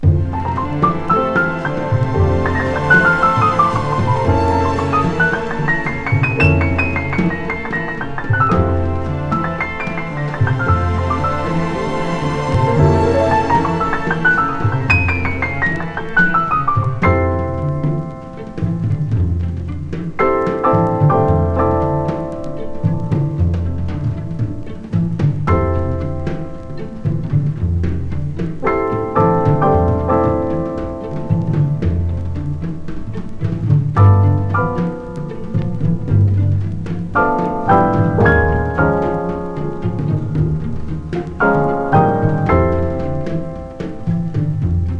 Jazz, Easy Listening, Lounge　USA　12inchレコード　33rpm　Stereo